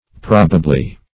Probably \Prob"a*bly\, adv.